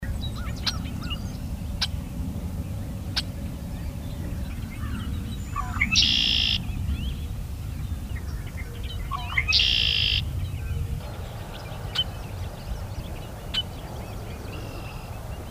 Their characteristic ‘rusty gate-hinge’ call is unmistakeable, whether in the coppice or heard deep in the mangroves 4 miles off-shore from a skiff in the Marls.
First, here’s the familiar call of a red-winged blackbird